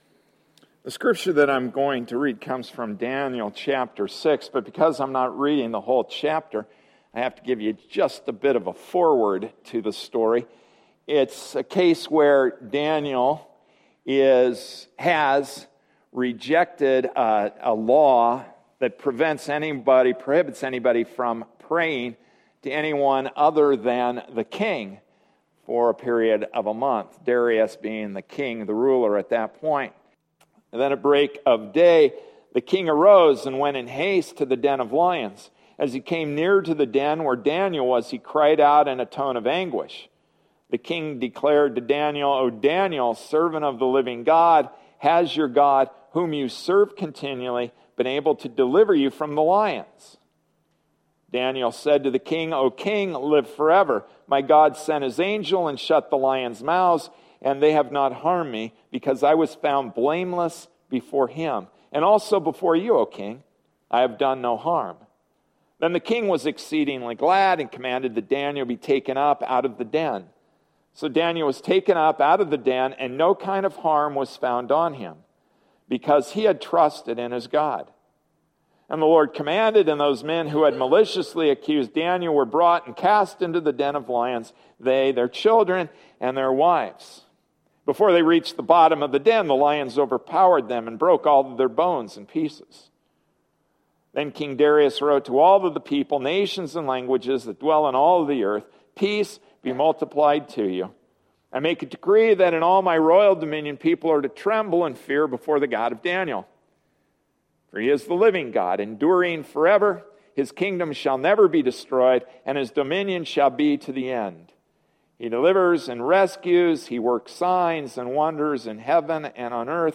Passage: Daniel 6:19-28 Service Type: Sunday Morning Service